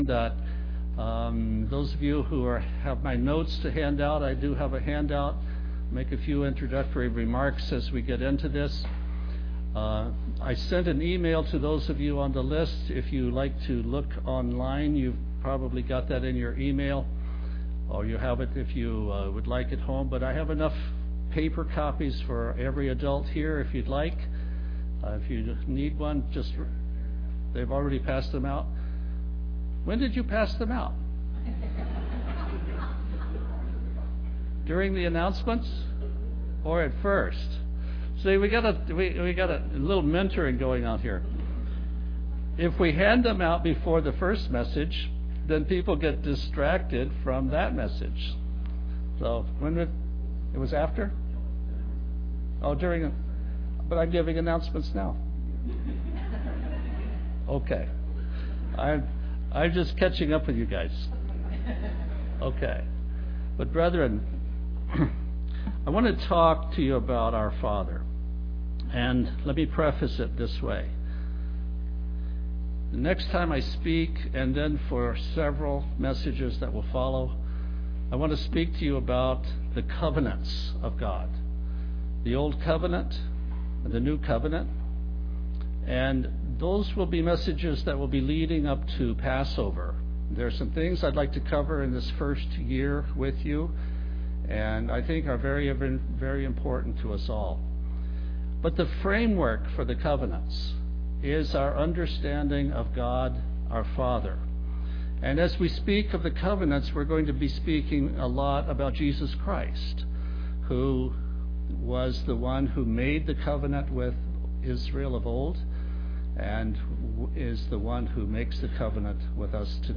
Given in Tacoma, WA
UCG Sermon Studying the bible?